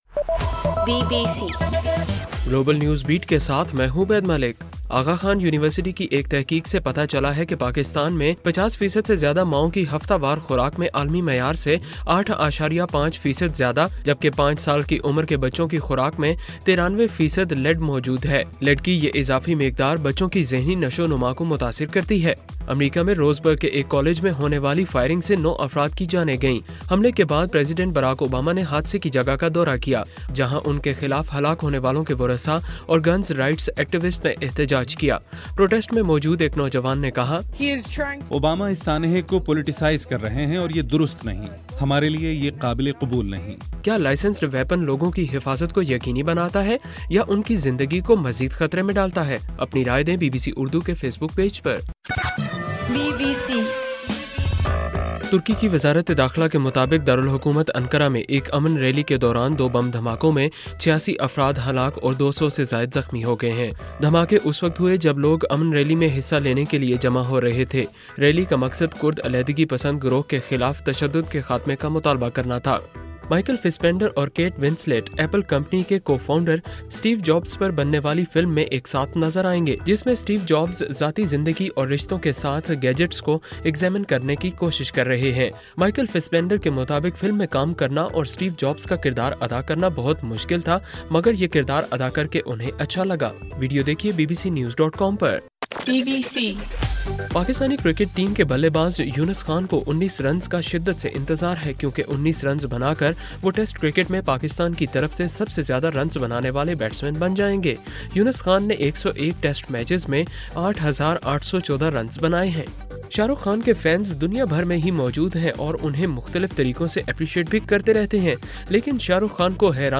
اکتوبر 10: رات 9 بجے کا گلوبل نیوز بیٹ بُلیٹن